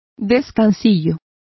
Also find out how descansillo is pronounced correctly.